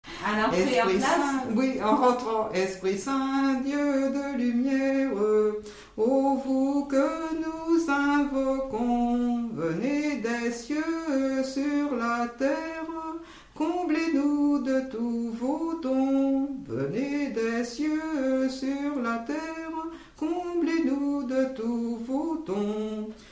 chanté pour rentrer en classe
Genre strophique
Pièce musicale inédite